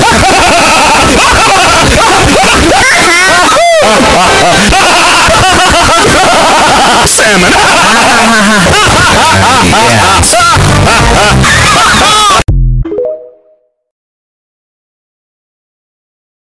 Sound Effects Soundboard0 views